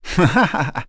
Zafir-Vox-Laugh.wav